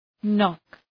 Προφορά
{nɒk}